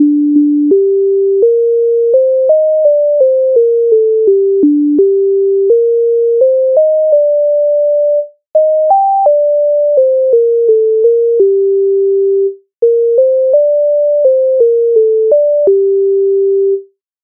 MIDI файл завантажено в тональності g-moll
Як не женився то й не журися Українська народна пісня з обробок Леонтовича с.104
Ukrainska_narodna_pisnia_Yak_ne_zhenyvsia_to_j_ne_zhurysia.mp3